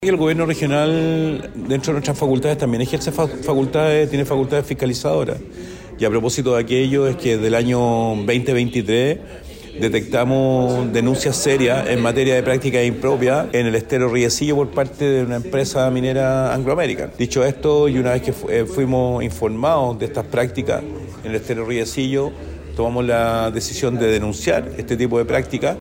El gobernador de la Región de Valparaíso, Rodrigo Mundaca, quien forma parte del movimiento Modatima, dedicado a la defensa de recursos naturales como el agua, anunció que el gobierno regional se hizo parte de las denuncias en contra de Anglo American después de tomar conocimiento de estas presuntas actividades ilegales.